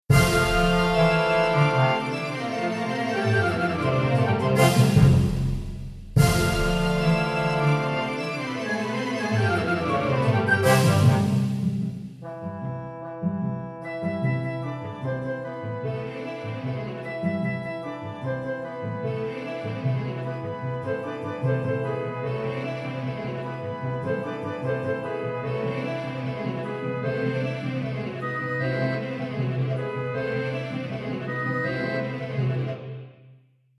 Orchestral Example 2